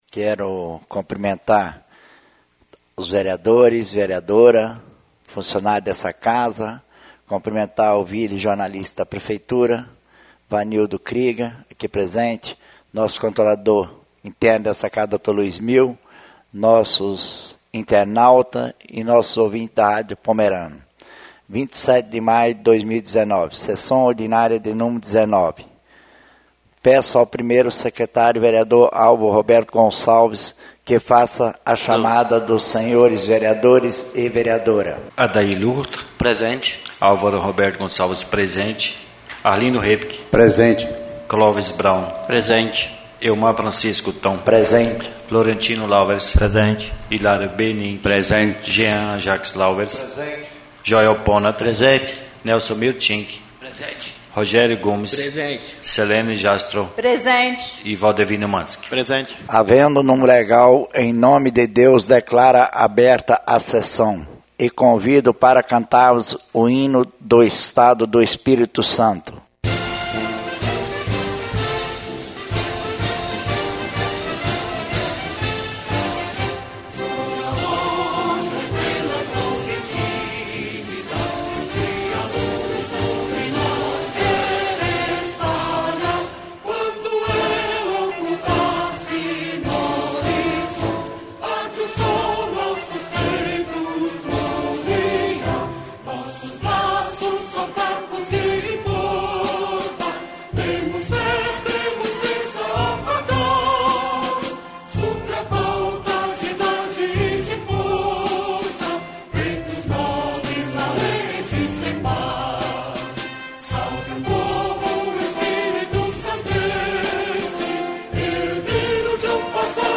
19ª Sessão Ordinária da 3ª Sessão Legislativa da 8ª Legislatura
SESSÃO ORDINÁRIA Nº 19/2019